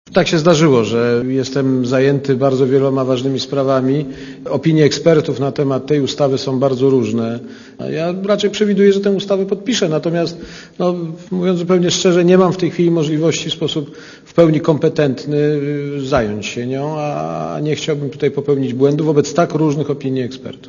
Źródło zdjęć: © Archiwum 30.11.2004 | aktual.: 01.12.2004 07:40 ZAPISZ UDOSTĘPNIJ SKOMENTUJ Komentarz audio Aleksader Kwaśniewski ma czas na podpisanie ustawy o PIT do 9 grudnia.